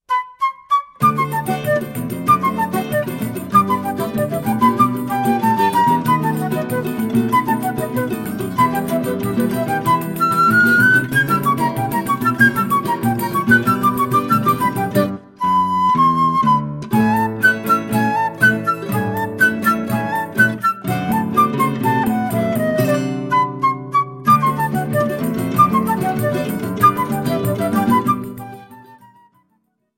flute
Choro ensemble